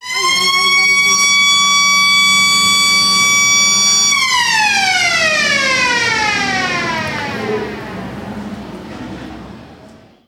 Sirena de salida de un colegio
sirena
Sonidos: Gente
Sonorización. Megafonia